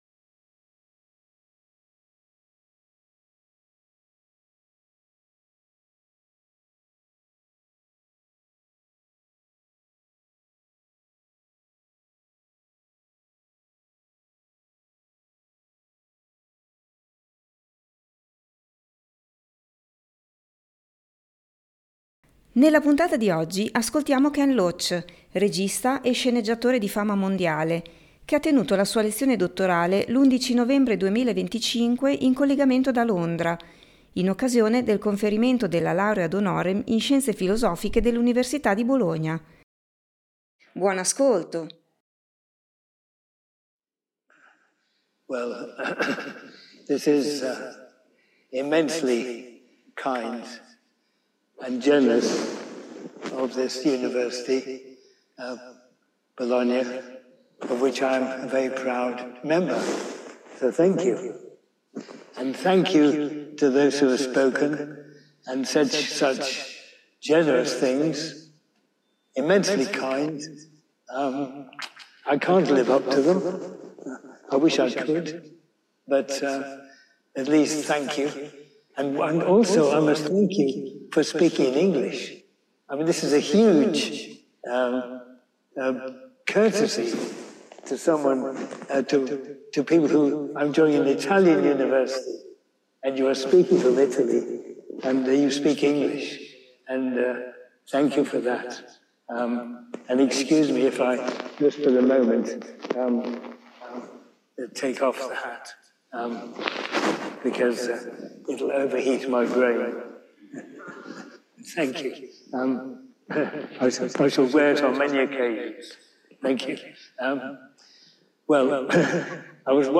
Ken Loach, regista e sceneggiatore di fama mondiale, ha tenuto la sua lezione dottorale l’11 novembre 2025 in collegamento da Londra in occasione del conferimento della Laurea ad honorem in Scienze filosofiche dell’Università di Bologna.